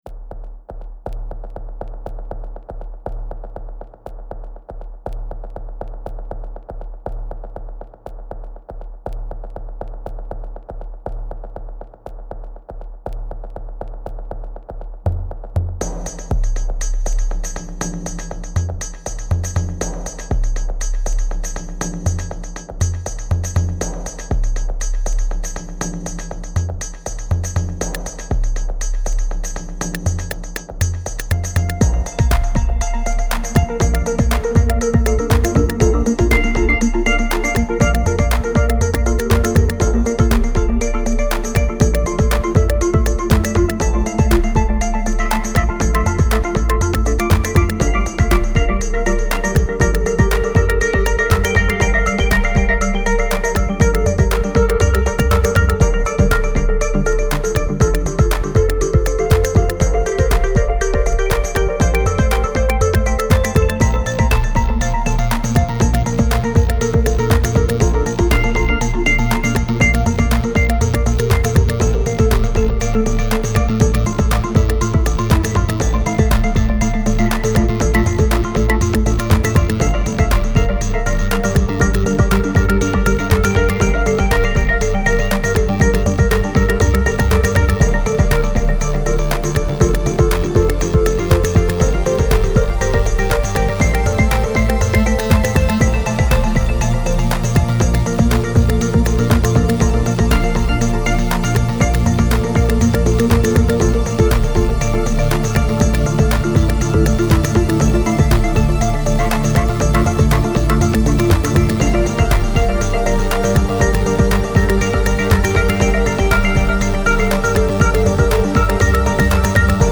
Tag: instrumental
This is not rock music.